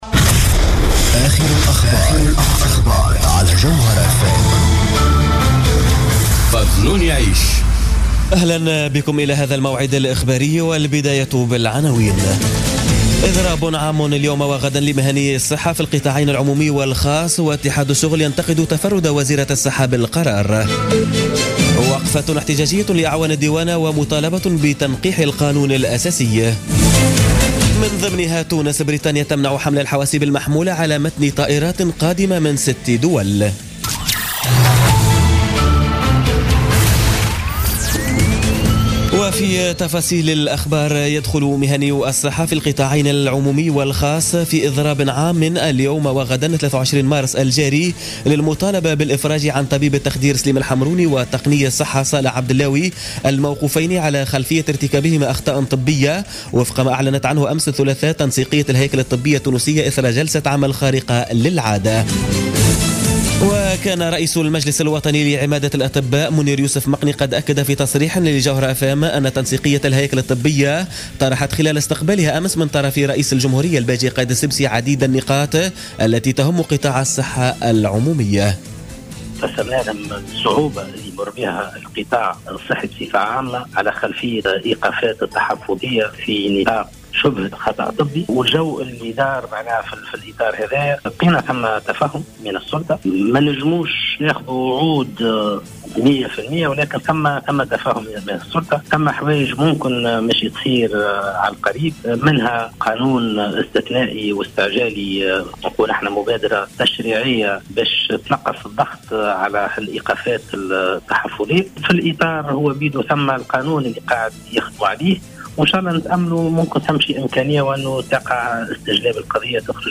نشرة أخبار منتصف الليل ليوم الاربعاء 22 مارس 2017